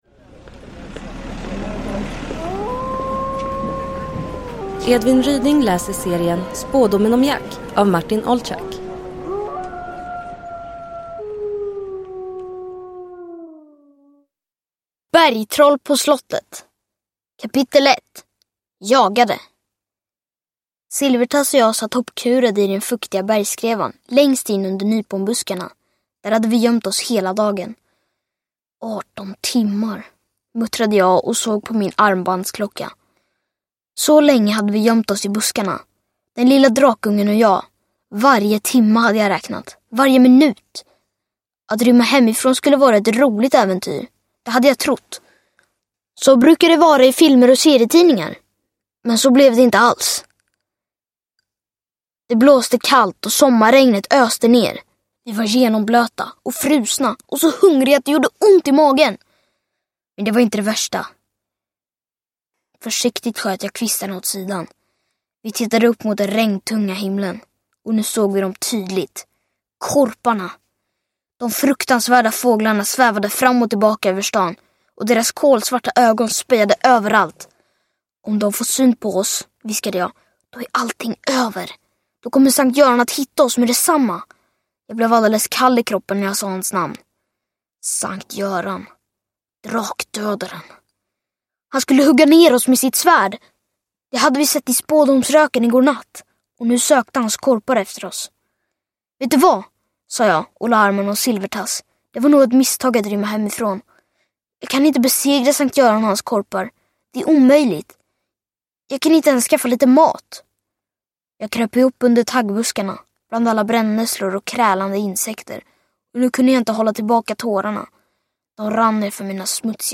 Bergtroll på slottet – Ljudbok – Laddas ner
Uppläsare: Edvin Ryding